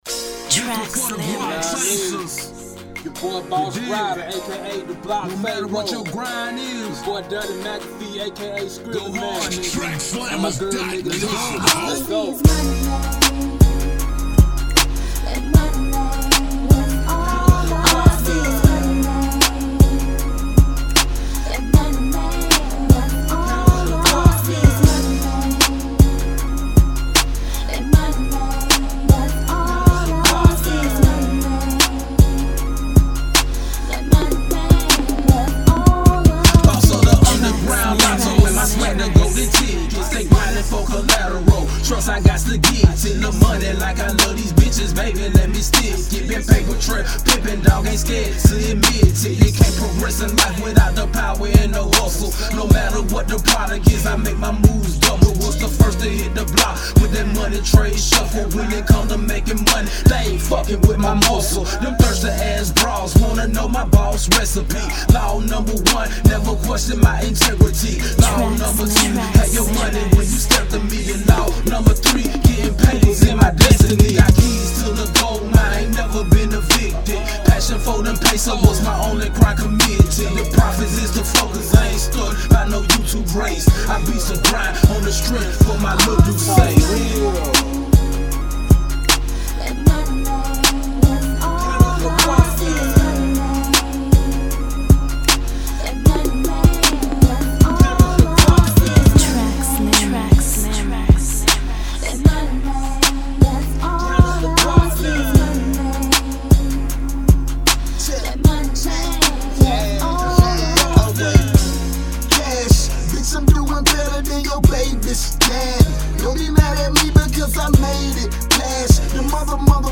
Genre: Gangsta Rap.